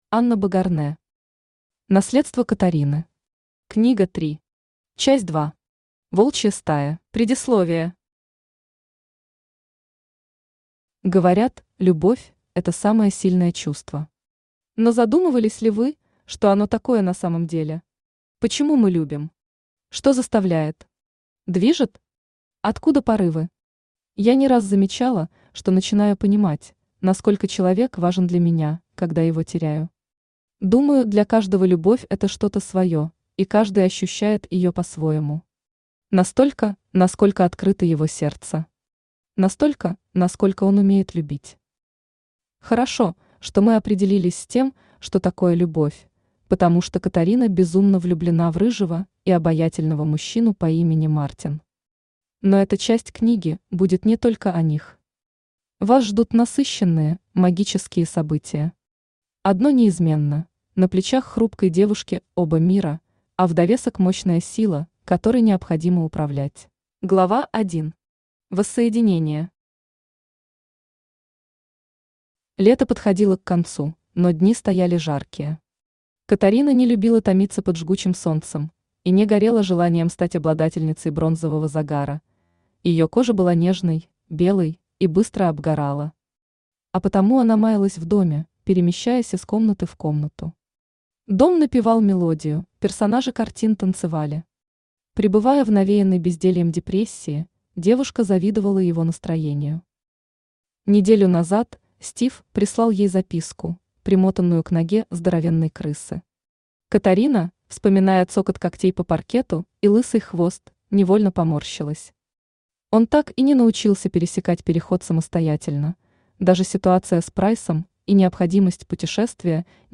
Аудиокнига Наследство Катарины. Книга 3. Часть 2. Волчья стая | Библиотека аудиокниг
Волчья стая Автор Анна Богарнэ Читает аудиокнигу Авточтец ЛитРес.